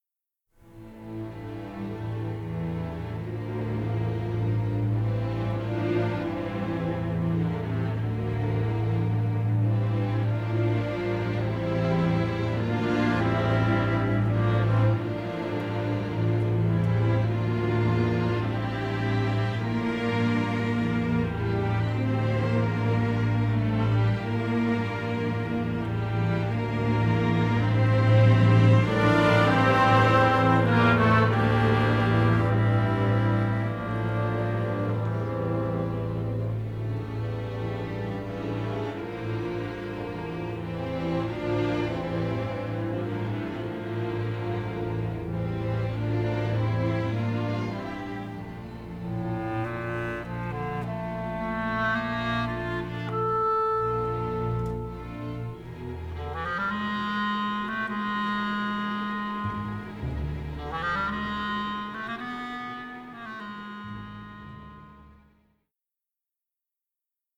rich symphonic score
three-channel stereo scoring session masters